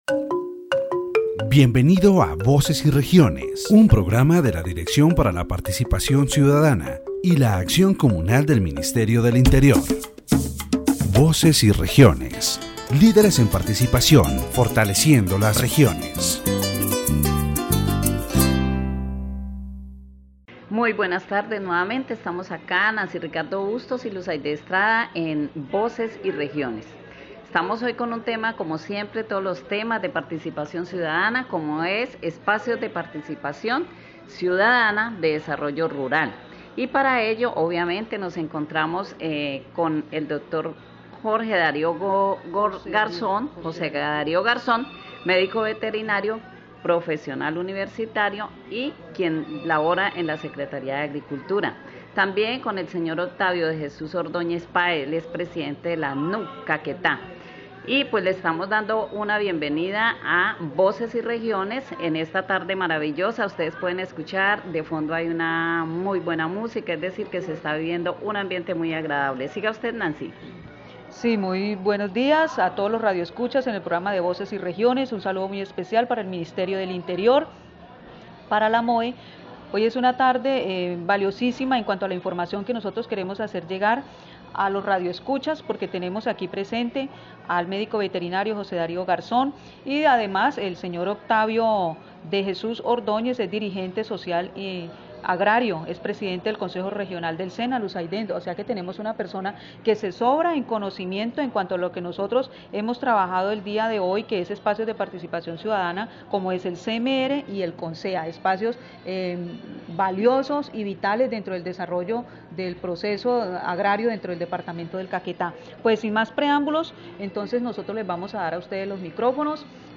The radio program "Voices and Regions" of the Directorate for Citizen Participation and Communal Action of the Ministry of the Interior focuses on spaces for citizen participation and rural development in the Department of Caquetá. During the program, the roles and functions of the Municipal Rural Development Council (CMDR) and the Sectional Agricultural Development Council (CONSEA) are discussed.